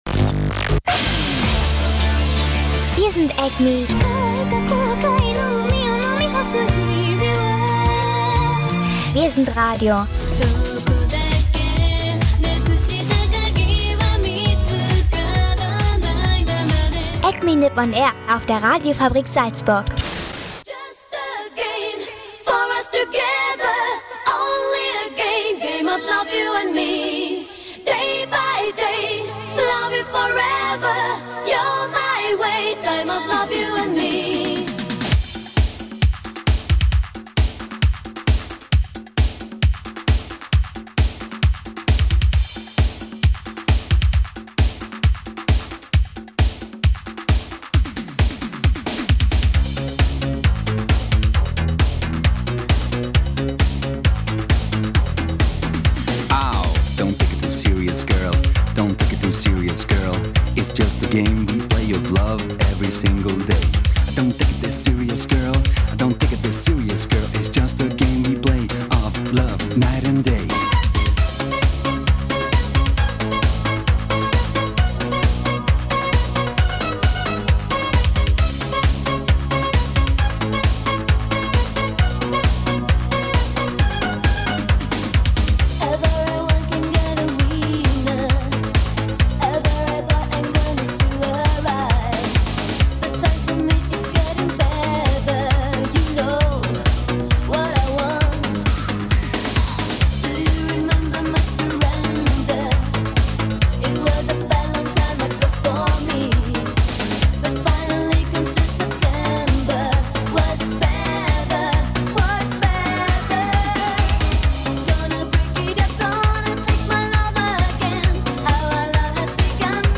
Wie Eurobeat klingt, in japanisch und englisch, gibt es diese Woche zu hören. Außerdem dazu aktuelle Nachrichten aus Japan und der Animeszene, u.a. zu Kyoto Animation.